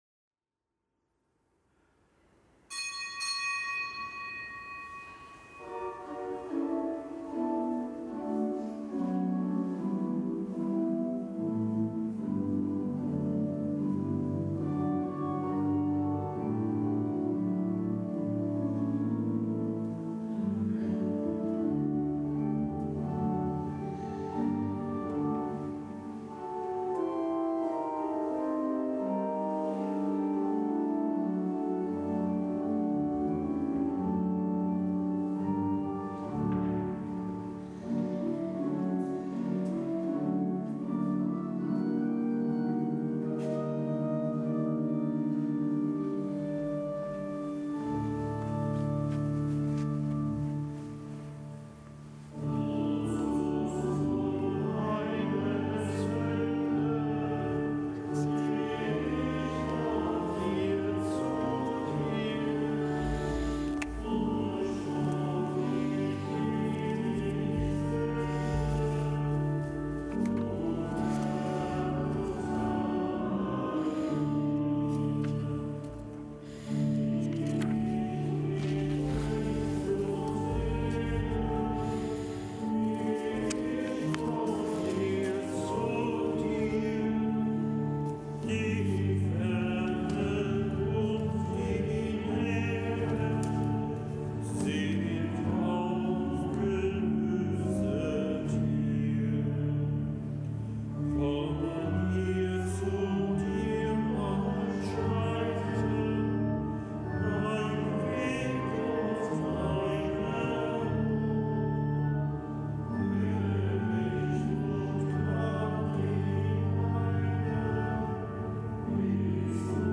Kapitelsmesse aus dem Kölner Dom am Dienstag der vierten Fastenwoche.
Zelebrant: Weihbischof Ansgar Puff.